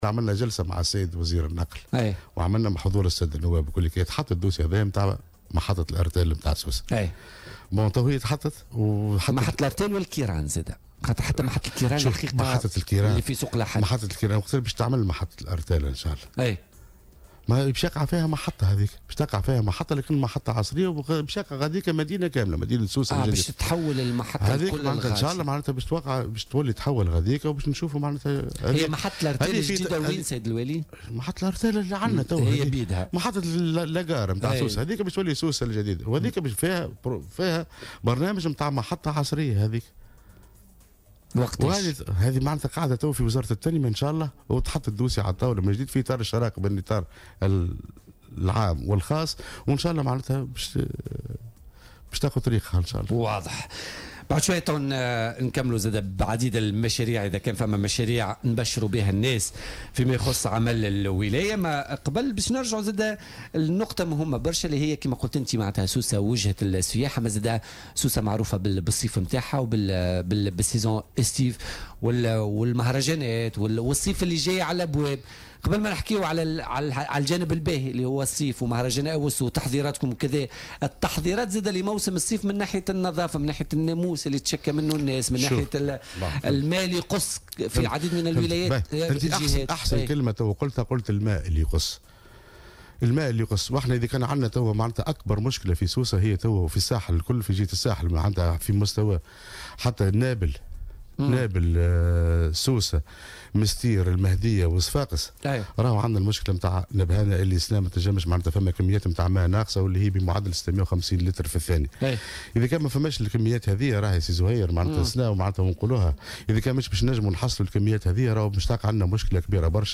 تحدث والي سوسة عادل الشليوي، ضيف برنامج "بوليتيكا" اليوم الخميس، عن عدد من المشاريع المبرمجة مستقبلا في الجهة.